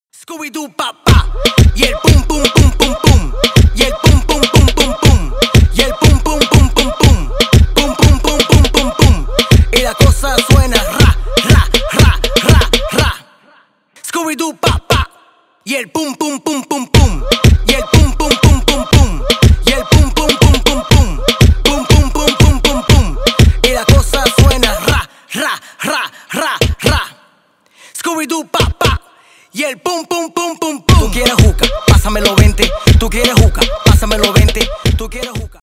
латинские
ремиксы